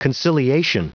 Prononciation du mot conciliation en anglais (fichier audio)
Prononciation du mot : conciliation